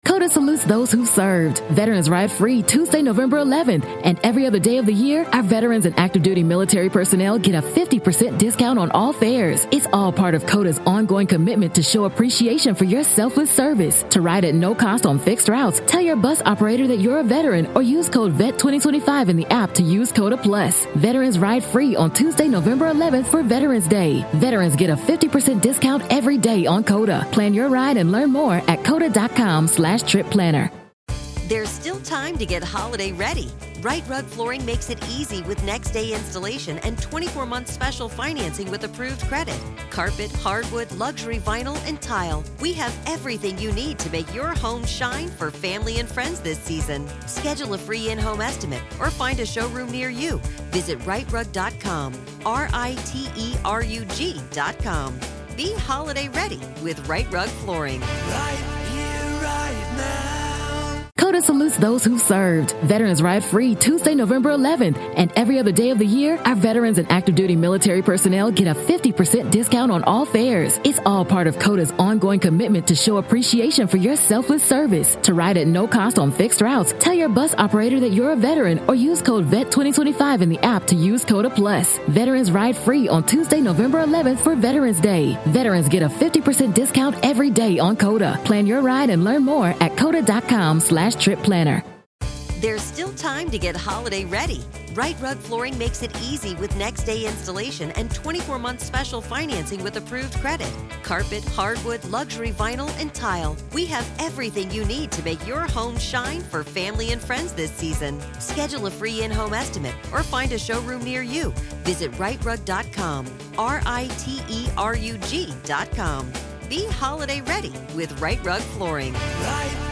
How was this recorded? RAW COURT AUDIO